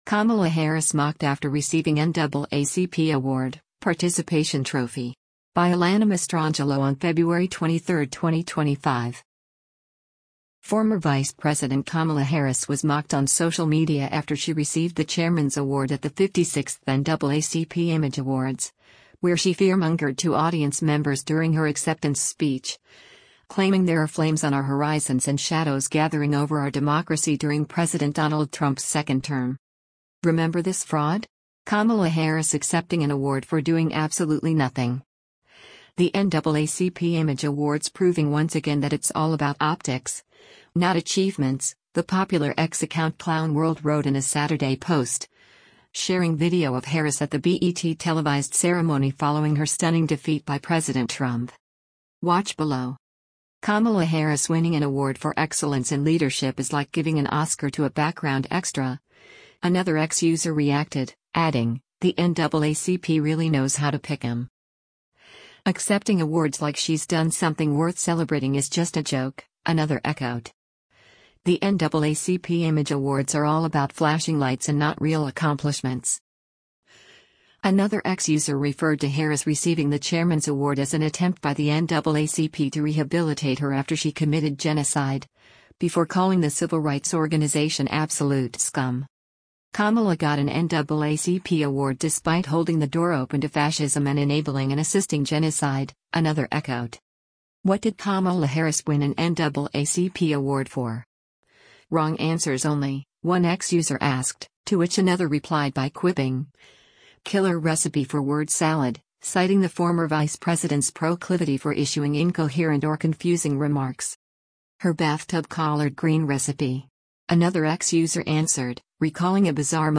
Former Vice President Kamala Harris was mocked on social media after she received the Chairman’s Award at the 56th NAACP Image Awards, where she fearmongered to audience members during her acceptance speech, claiming there are “flames on our horizons” and “shadows gathering over our democracy” during President Donald Trump’s second term.